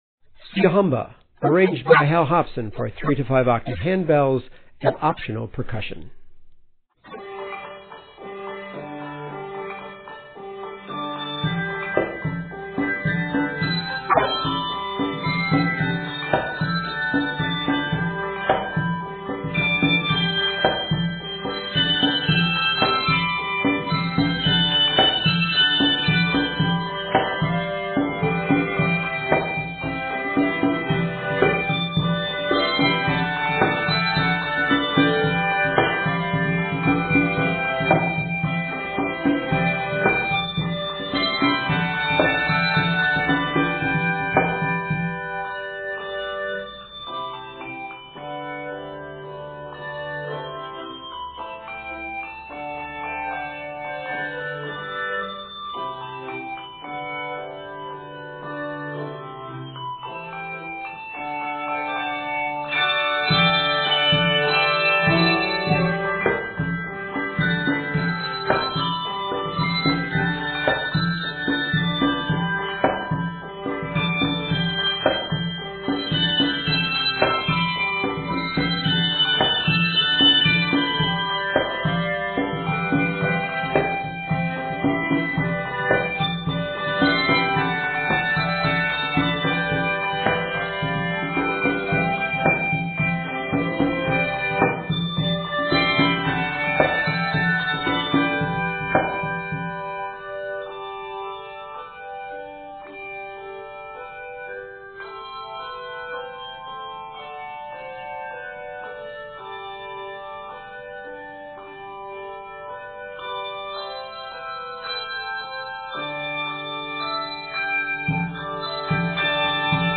3 to 5-octave setting